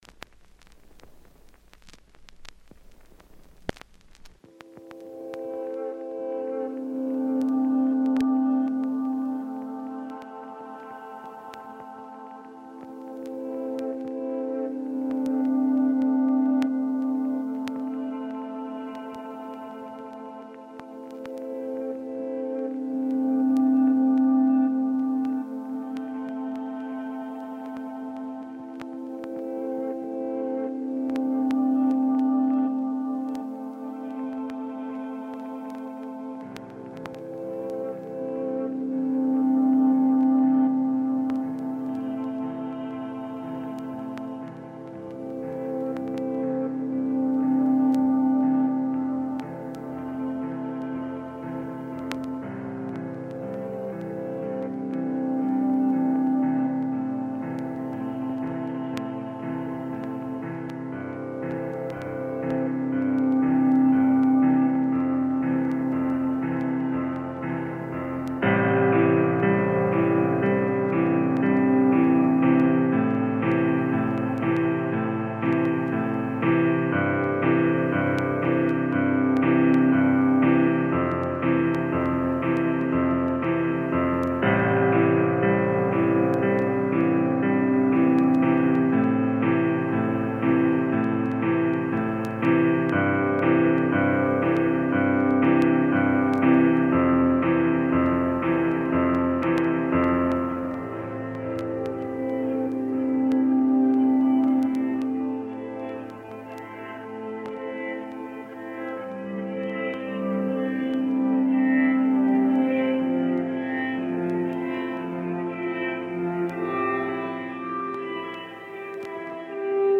Tagged as: Ambient, Experimental